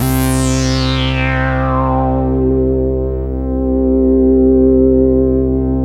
SYN_JD-800 1.3.wav